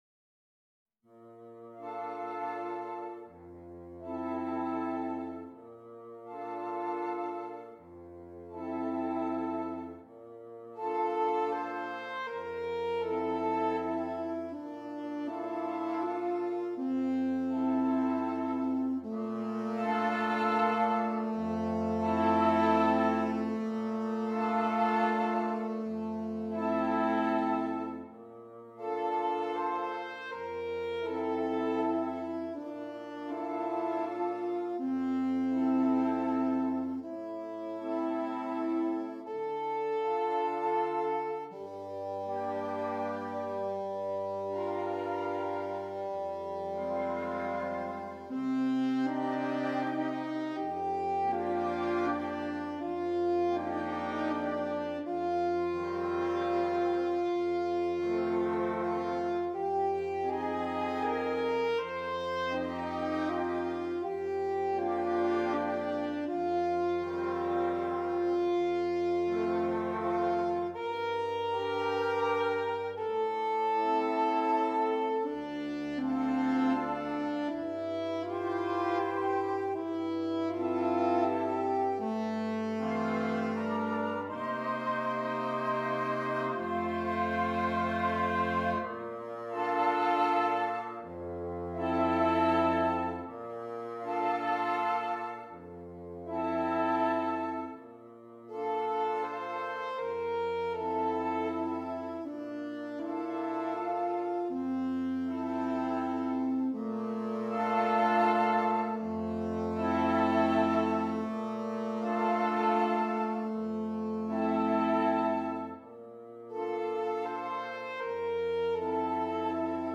Interchangeable Woodwind Ensemble
PART 1 - Flute, Oboe, Clarinet
PART 3 - Clarinet, Alto Saxophone, F Horn
PART 5 - Bass Clarinet, Bassoon, Baritone Saxophone